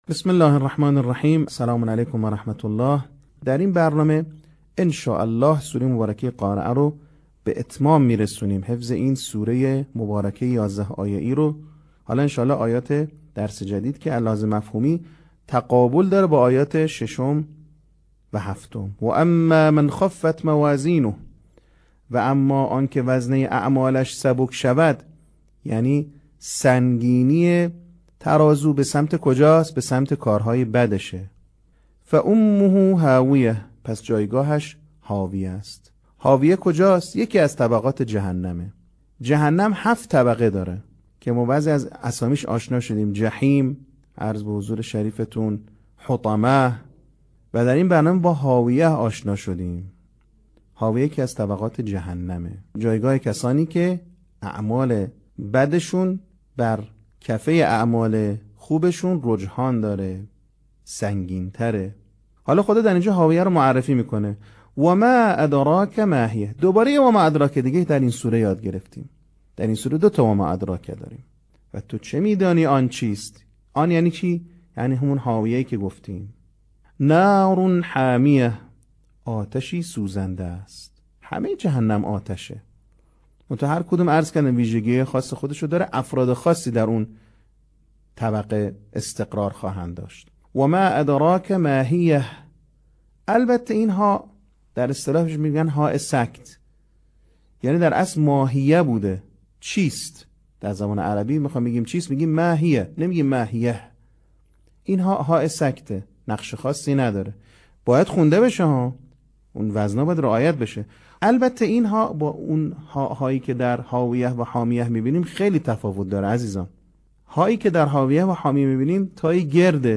صوت | بخش پنجم آموزش حفظ سوره قارعه
به همین منظور مجموعه آموزشی شنیداری (صوتی) قرآنی را گردآوری و برای علاقه‌مندان بازنشر می‌کند.